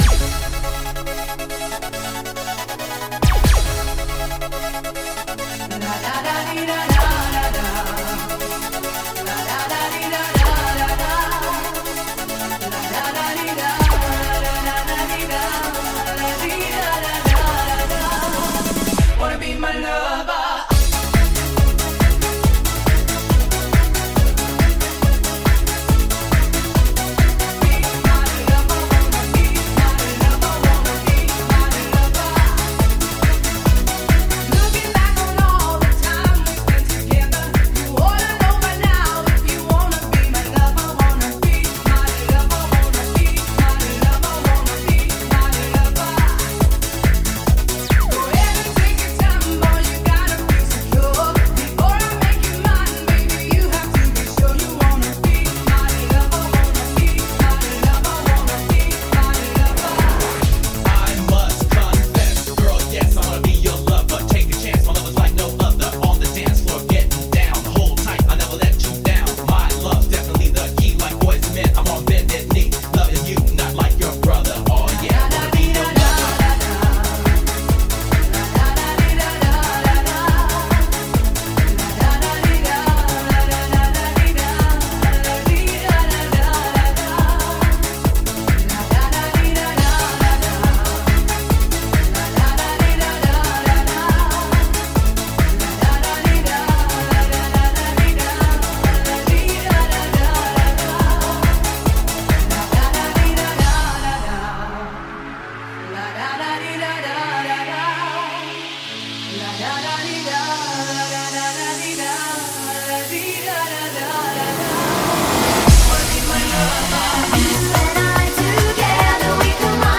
Лучшие танцевальные 90 __ Vol 9 __